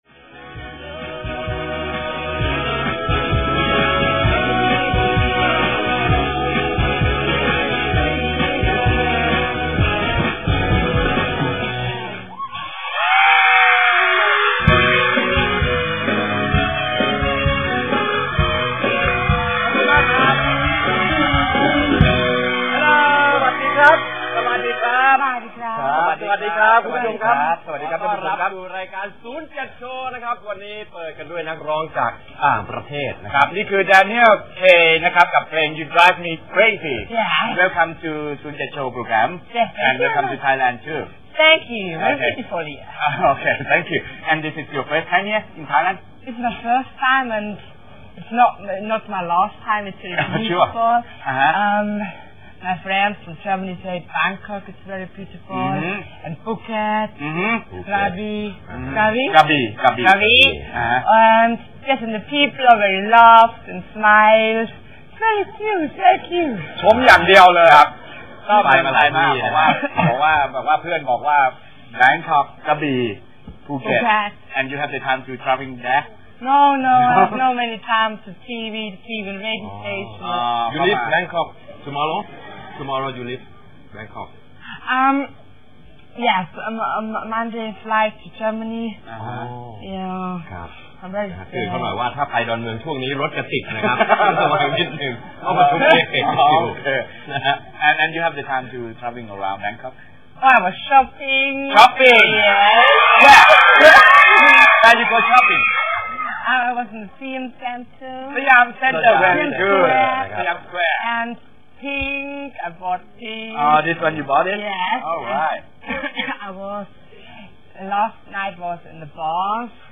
17.10.2003 Daniel zu Besuch bei Radio Virgin 95.5
Auf seiner triumphalen Tour durch Thailand ist Daniel auch bei einigen Radiosendern -heute bei Radio 95.5 "Virgin Hits"- zu Gast. Bewunderswert, wie charmant er die Interviews zwischen den Sprachen Thailändisch, Englisch und Niederbayerisch meistert.